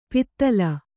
♪ pittala